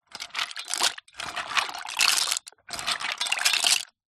На этой странице собраны разнообразные звуки, связанные с макаронами: от шуршания сухих спагетти до бульканья кипящей воды.
Звук подъема и погружения макарон в кипящую воду